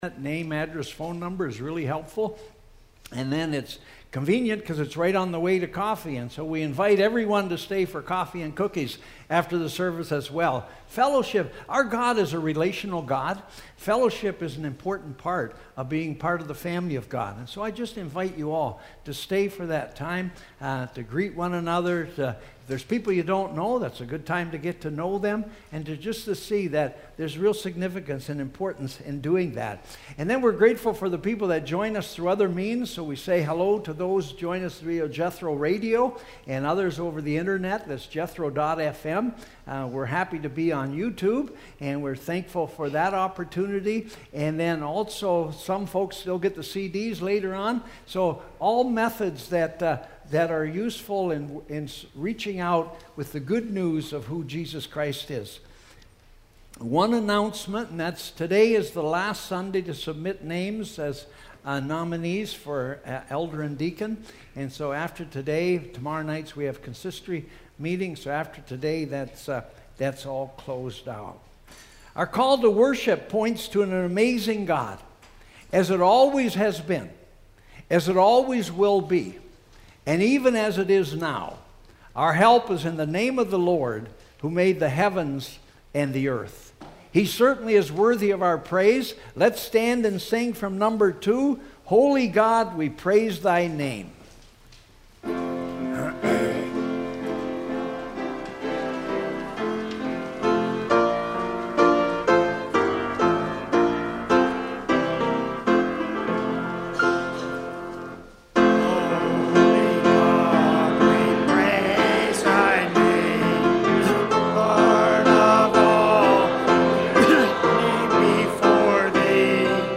Worship Services | Eighth Reformed Church